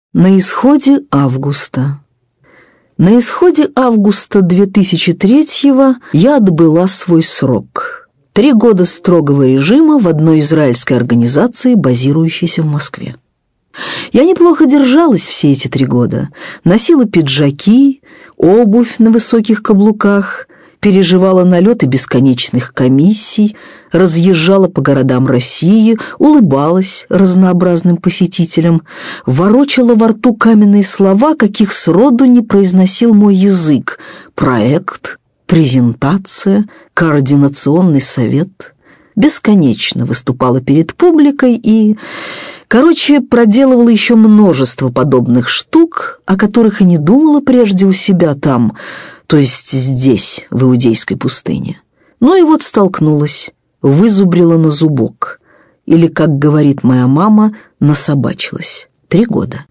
Аудиокнига Новеллы о путешествиях (сборник) | Библиотека аудиокниг
Aудиокнига Новеллы о путешествиях (сборник) Автор Дина Рубина Читает аудиокнигу Дина Рубина.